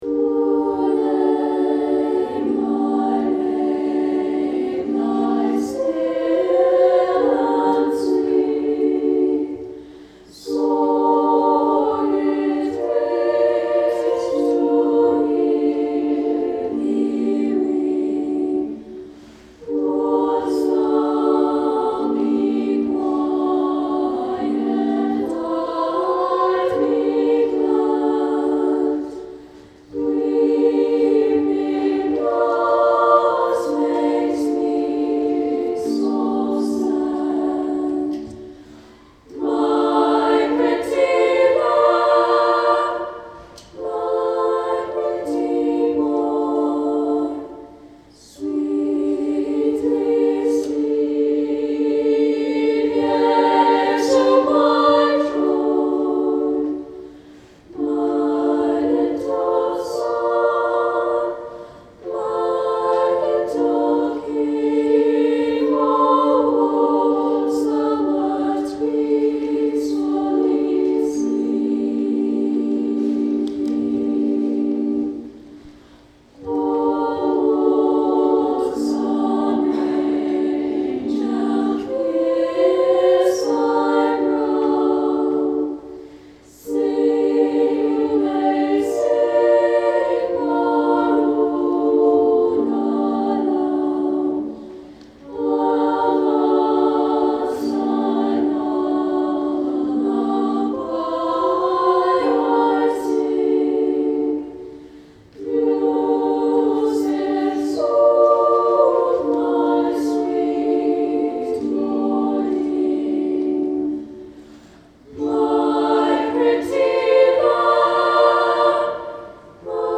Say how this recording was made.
Anthem , Christmas-Vigil